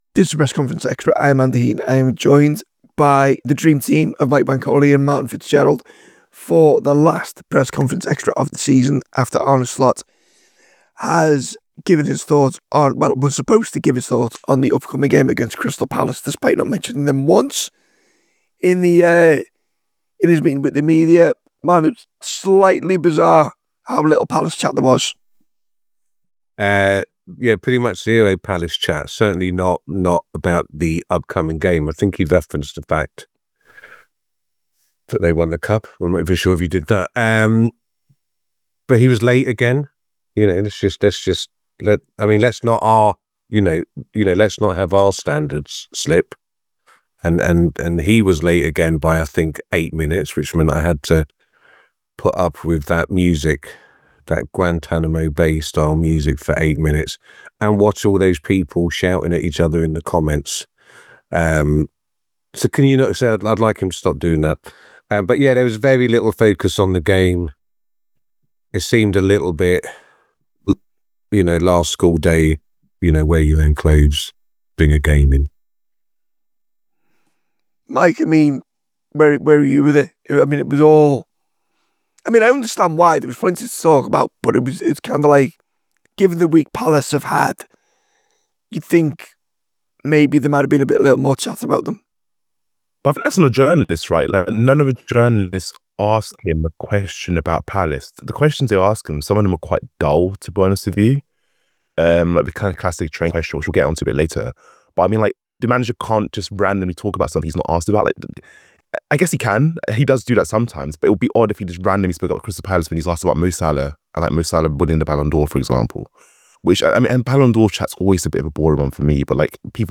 Below is a clip from the show – subscribe for more on the Liverpool v Crystal Palace press conference…